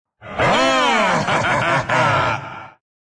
jak_laugh_04.mp3